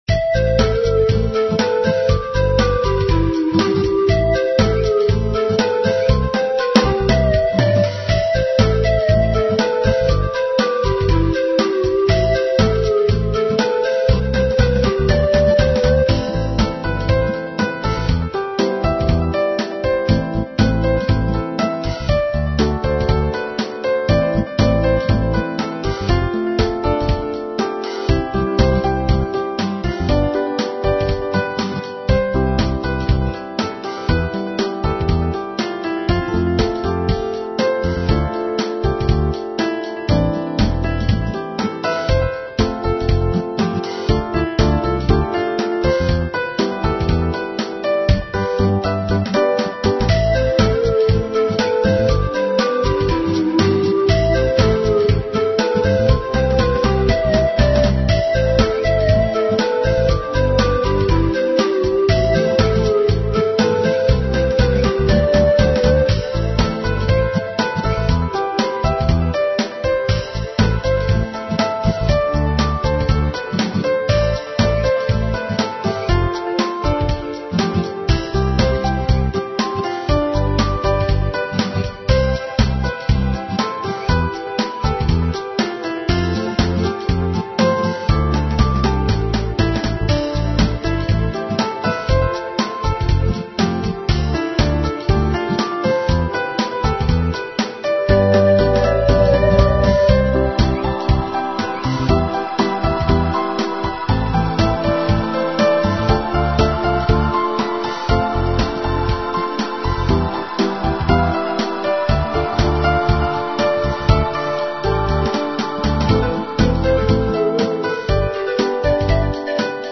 Rhythmic Instrumental Pop Composition